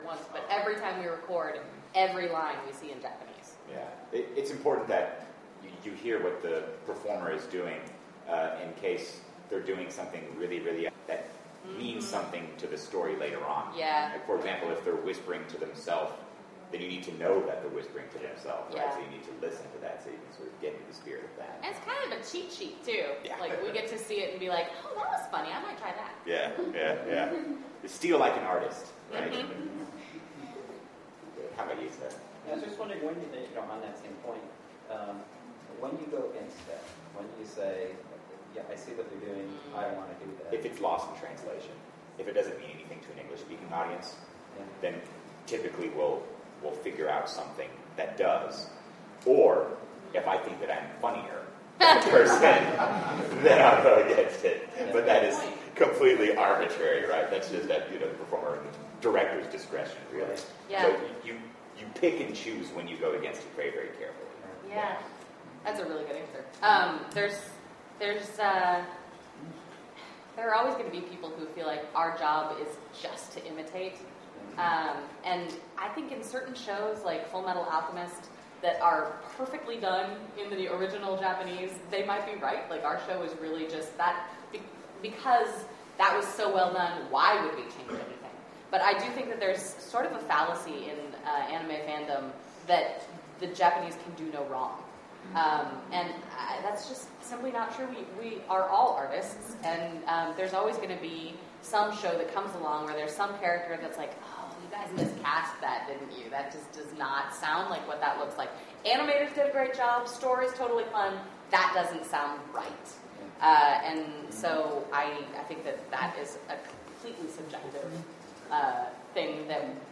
Interviews with interesting people about interesting, geeky things.